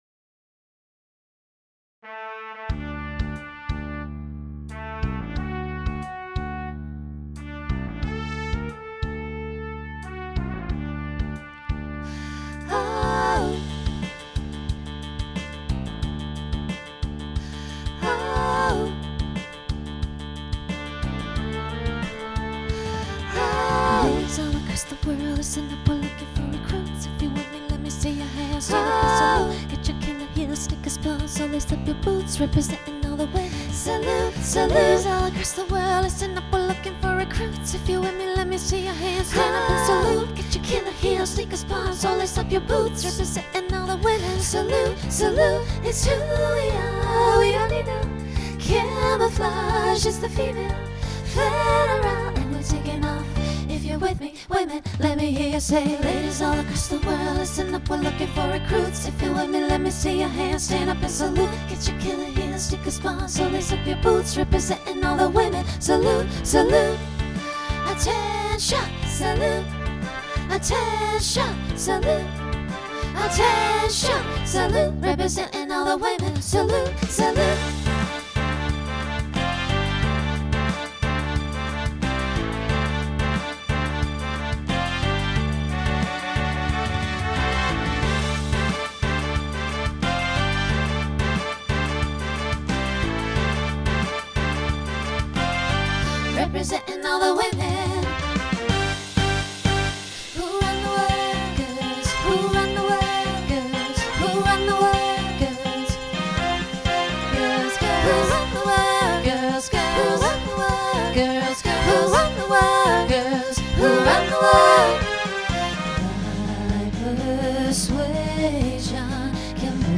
Genre Pop/Dance Instrumental combo
Show Function Opener Voicing SSA